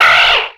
Cri de Zigzaton dans Pokémon X et Y.